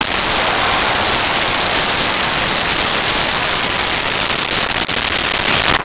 Large Crowd Applause 04
Large Crowd Applause 04.wav